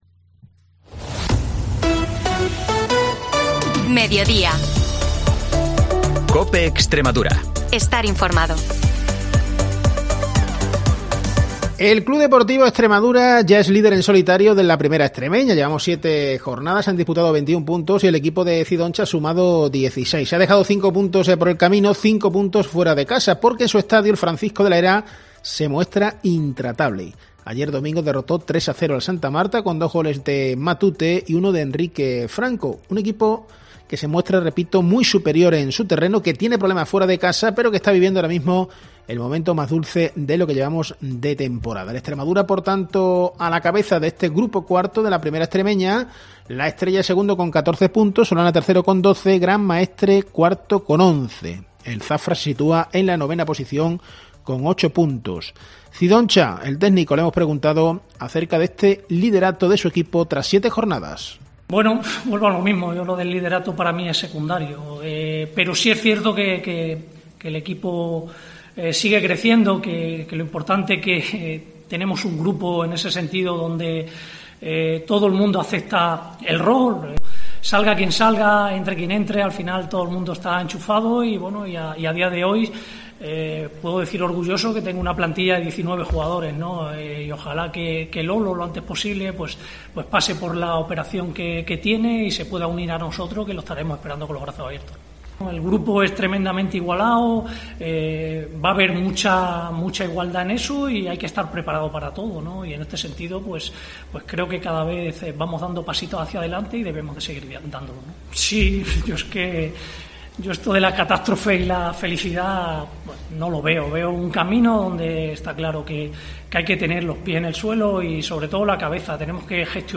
Información y entrevistas de Almendralejo-Tierra de Barros y Zafra-Río Bodión, de lunes a jueves, de 13.50 a 14 horas